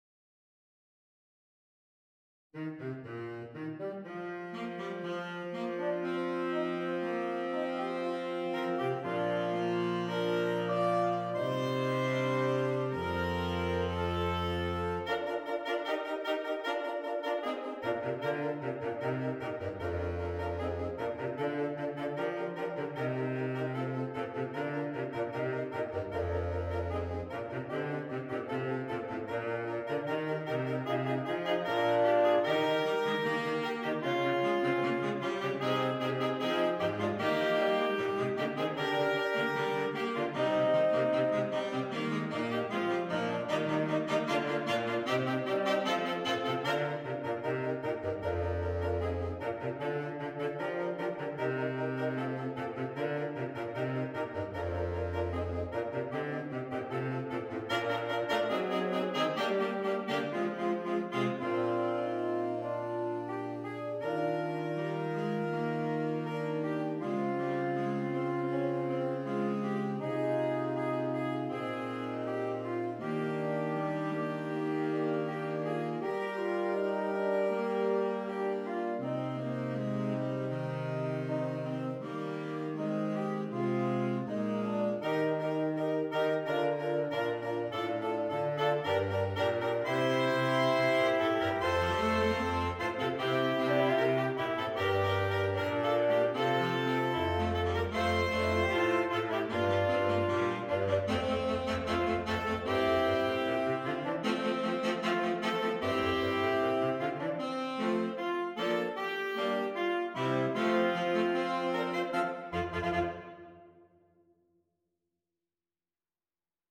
Saxophone Quartet (SATB or AATB)
Traditional
This is a spirited arrangement